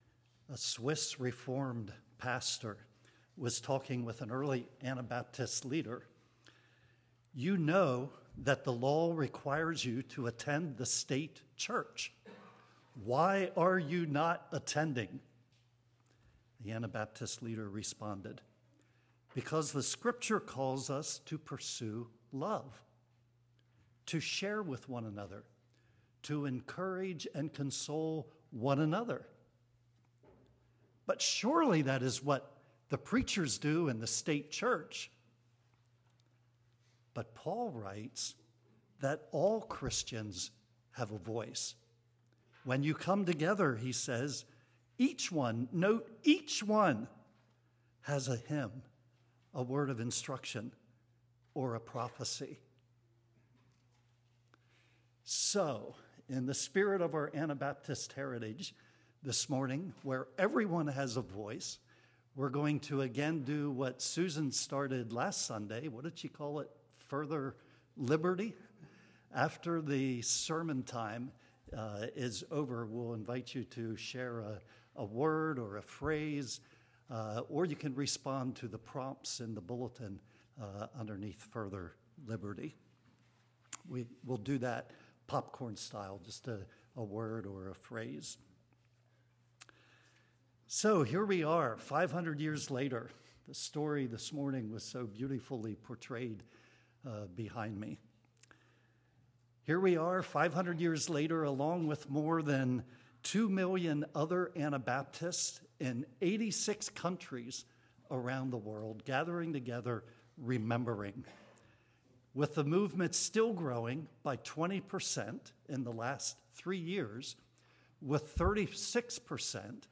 1/19/25 Sermon